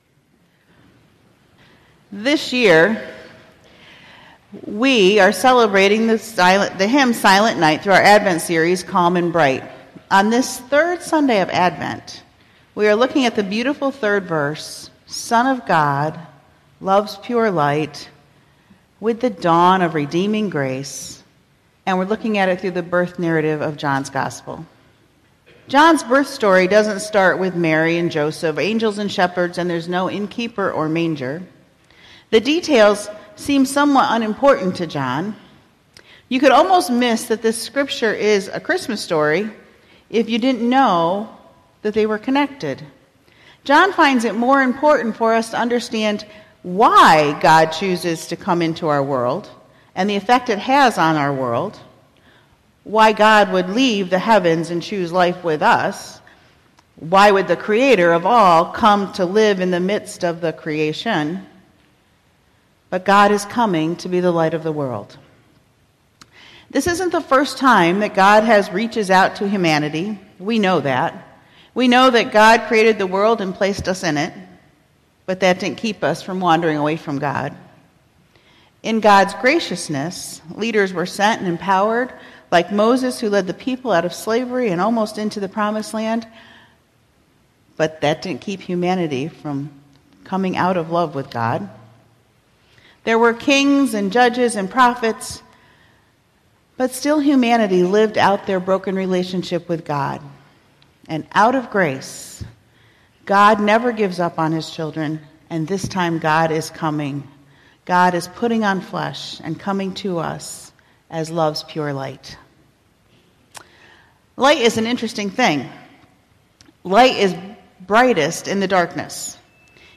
Dec1618-Sermon.mp3